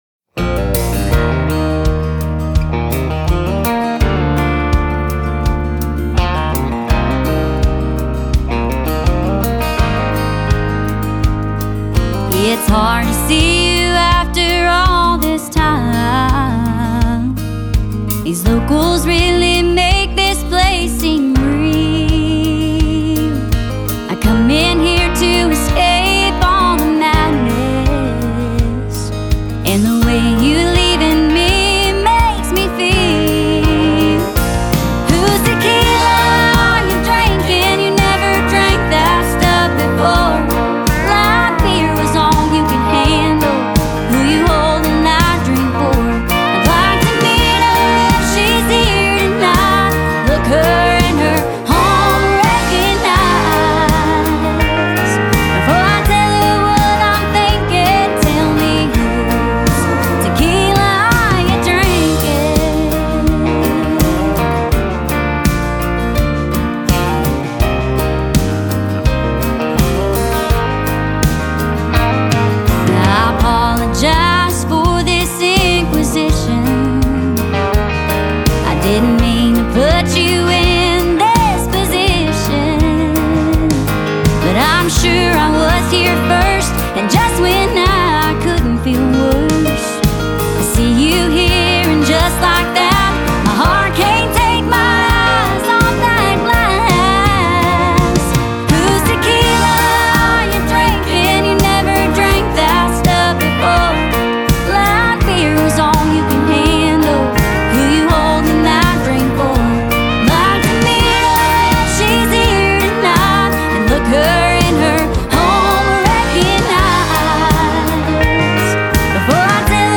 SONG GENRE – COUNTRY